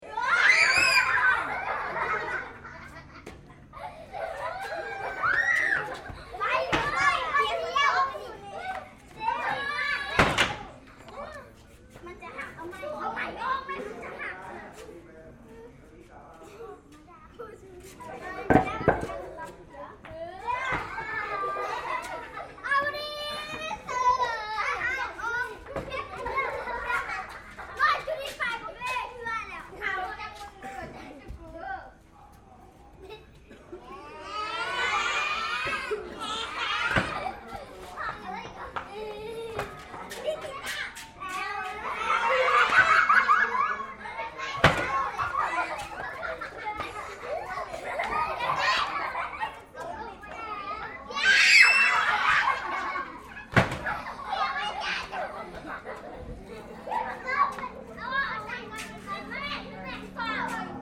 1.4 MB Ten kids, one makeshift teeter totter.